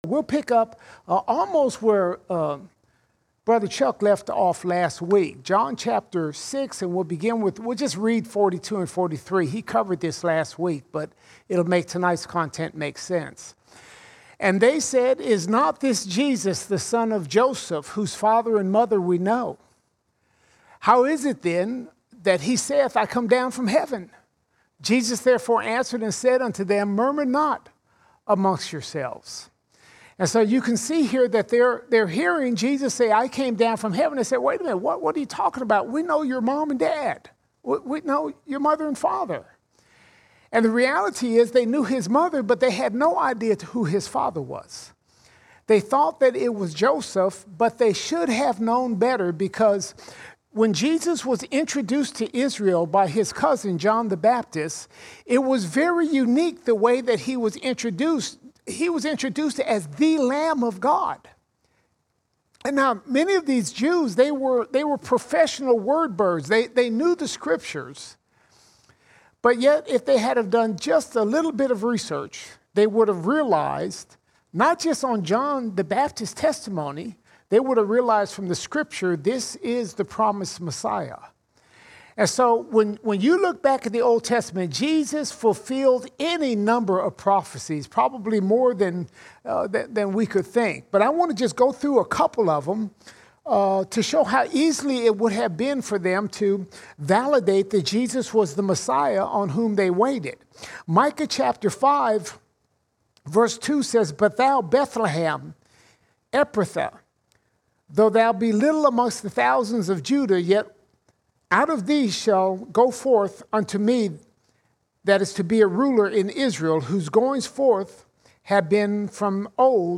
13 October 2022 Series: John All Sermons John 6:46 to 6:71 John 6:46 to 6:71 Jesus speaks spiritual words that cause many of His followers to leave Him.